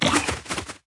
Media:RA_Hog Rider_Evo.wav UI音效 RA 在角色详情页面点击初级、经典和高手形态选项卡触发的音效